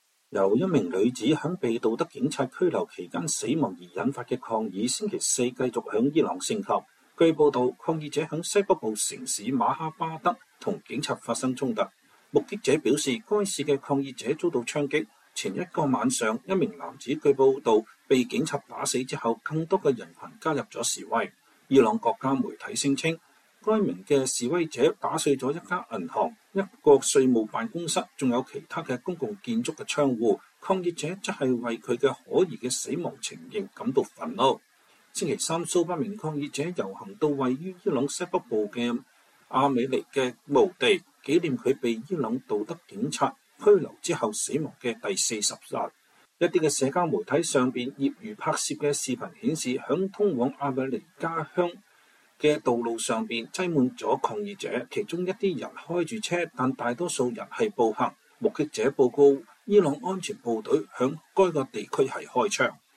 視頻裡可以聽到這些示威者高喊“獨裁者去死”，還有其他的口號，他們的遊行無視了為周三的進一步抗議而實施的強力安全措施。